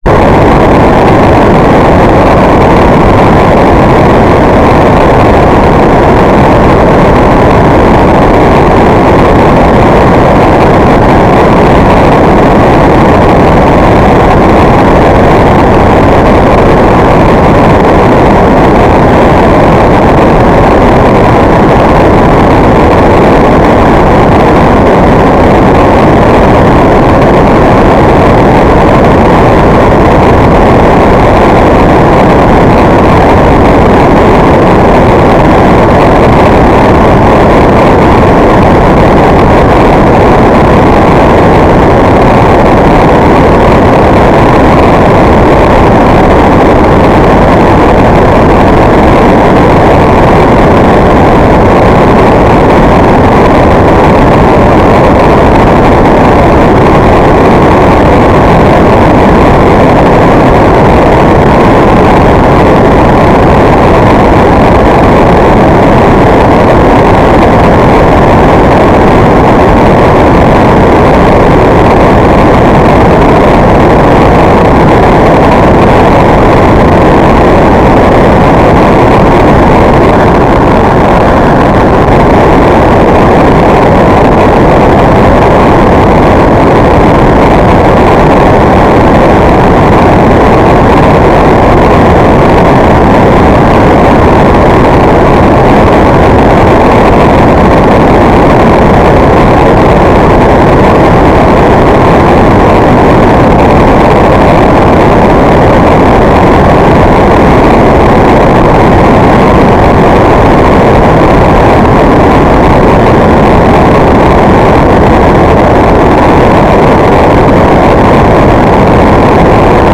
"transmitter_description": "Mode U - FSK1k2",